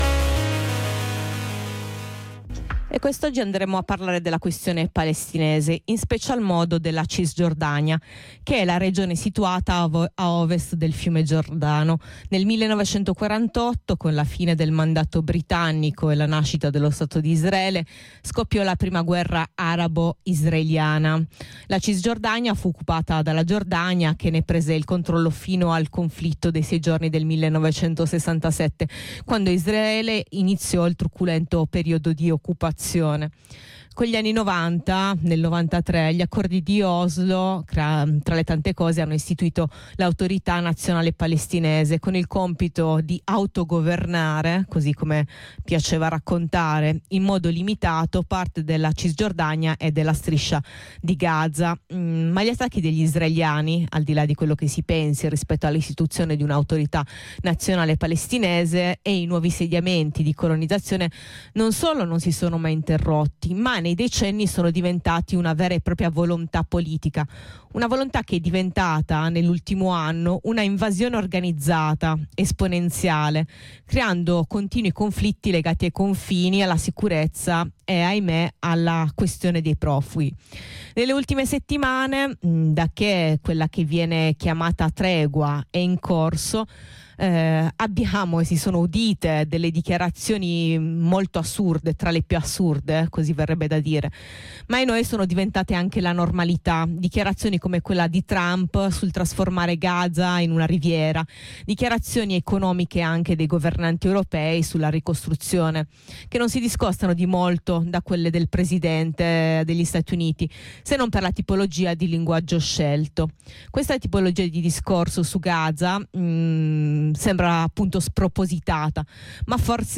ricercatrice italo-palestinese